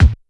Kick_52.wav